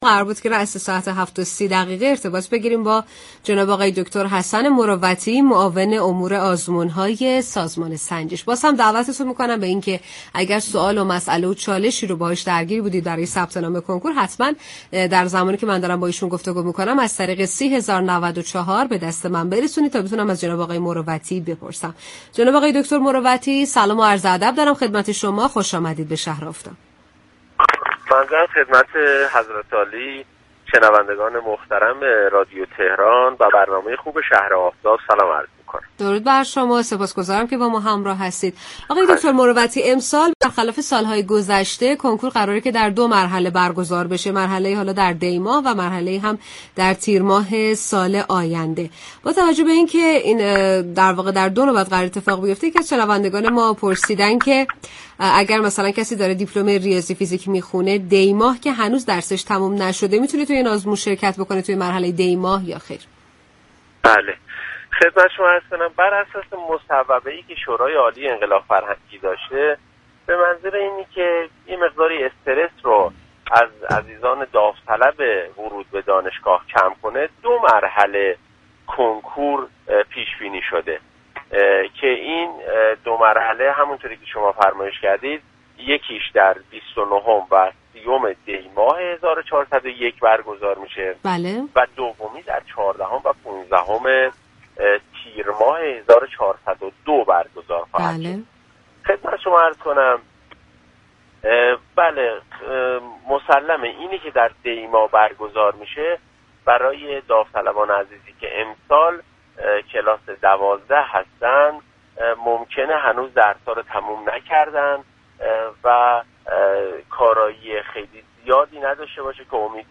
به گزارش پایگاه اطلاع رسانی رادیو تهران؛ حسن مروتی معاون امور آزمون‌های سازمان سنجش آموزش كشور در گفت و گو با "شهر آفتاب" رادیو تهران از برگزاری آزمون سال 1402 در دو نوبت دی ماه 1401 و تیرماه 1402 خبر داد و گفت: این تصمیم به منظور كاهش استرس شركت كنندگان در كنكور سال 1402 است.